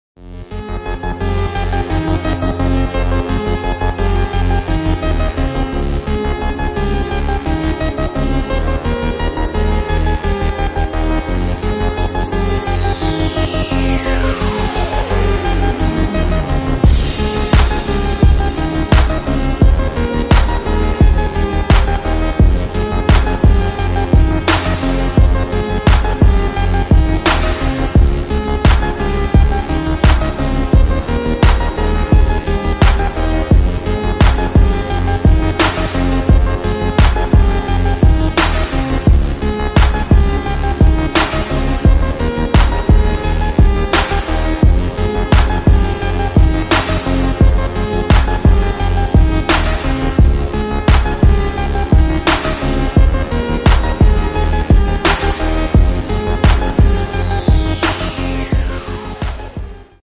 (sʟᴏᴡᴇᴅ + ʀᴇᴠᴇʀʙ)